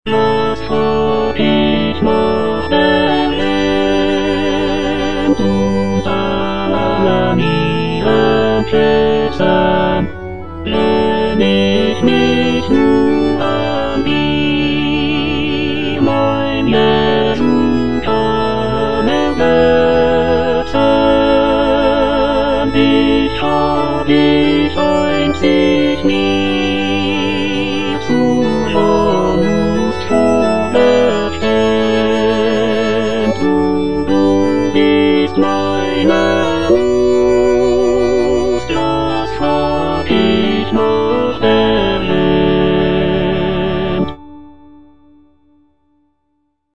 J.S. BACH - CANTATA "SEHET, WELCH EINE LIEBE" BWV64 Was frag' ich nach der Welt - Tenor (Emphasised voice and other voices) Ads stop: auto-stop
Cantata "Sehet, welch eine Liebe" BWV 64 is a sacred vocal work composed by Johann Sebastian Bach.